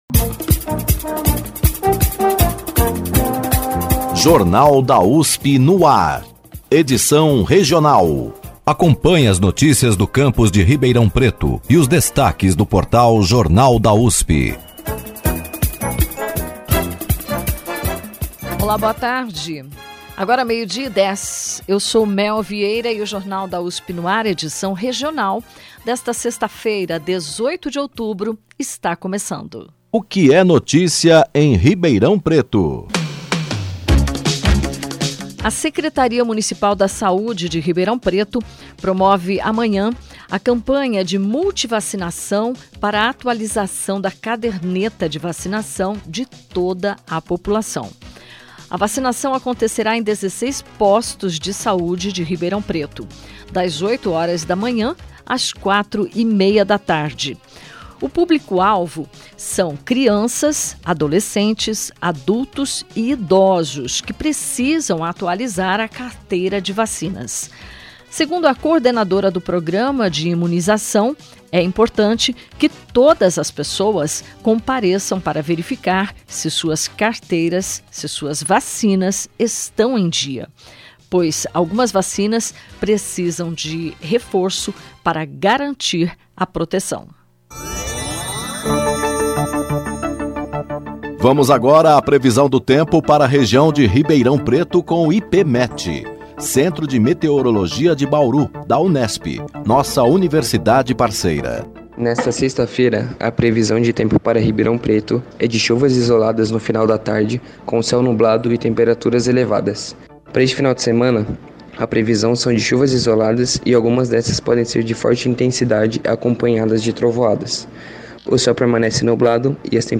O Jornal da USP no Ar – Edição Regional desta sexta-feira (18/10) informa, entre outras notícias, que a Secretaria Municipal da Saúde de Ribeirão Preto promove amanhã a campanha de multivacinação para atualização da caderneta de vacinação de toda a população. Você também vai ouvir os destaques do Jornal da USP e da home Ribeirão Preto do Jornal da USP, além de reportagens da Rádio USP e de rádios parceiras, como a que fala sobre a UFSCar, que conquistou duas premiações e uma menção honrosa no Prêmio Capes de Tese 2024 como as melhores teses defendidas no ano passado.